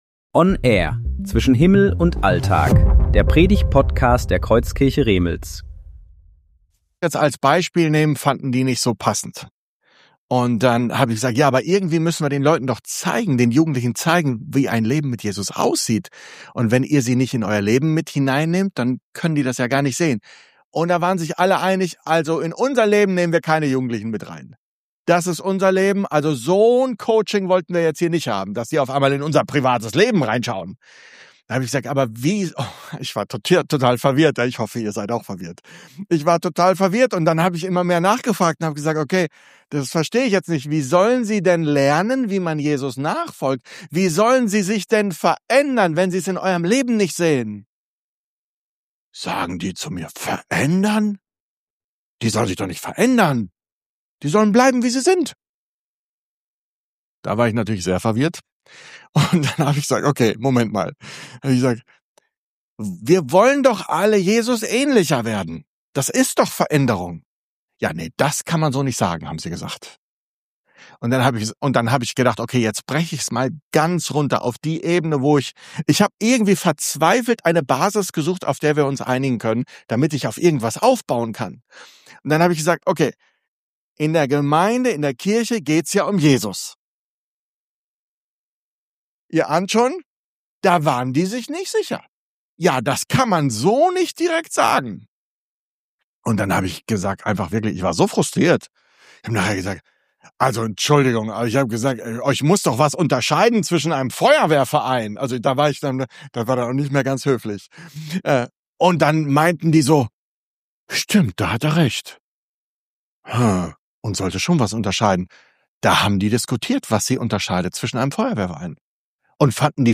Predigtserie: Vorträge
Hinweis: Leider hat es die erste Minute des Vortrags nicht in die Aufnahme geschafft – dennoch ist der Vortrag absolut hörenswert und inhaltlich vollständig gut nachvollziehbar.